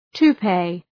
Προφορά
{tu:’peı}
toupee.mp3